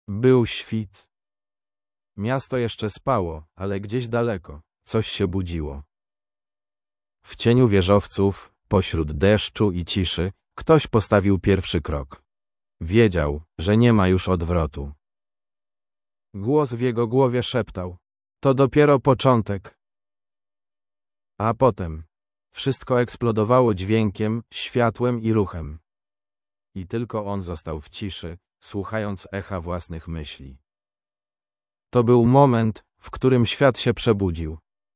Oto kompletny kod wykorzystujący Google Cloud Text-to-Speech API:
Poniżej przykład do pobrania jak to wyszło.
Pobierz by usłyszeć plik mp3 przygotowany przez SSML